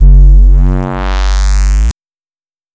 Desecrated bass hit 08.wav